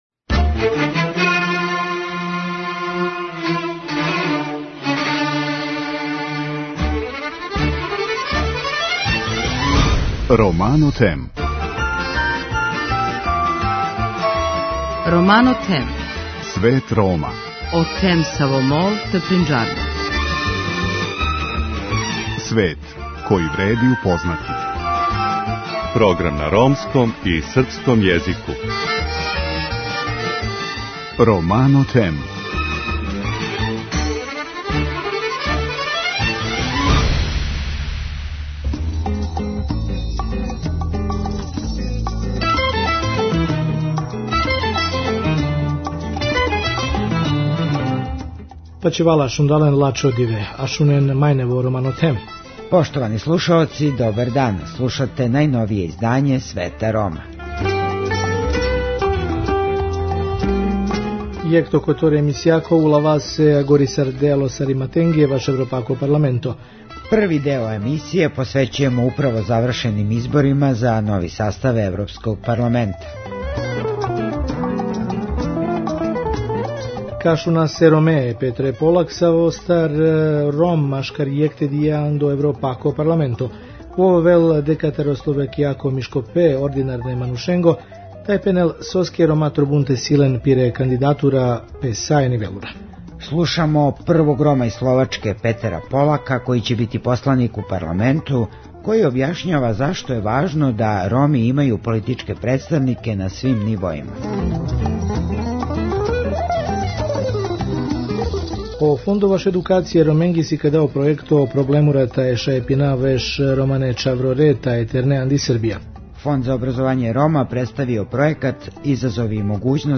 Слушамо првог Рома из Словачке, Петера Полака, који ће бити посланик у Парламету. Објашњава зашто је важно да Роми имају политичке представнике на свим нивоима.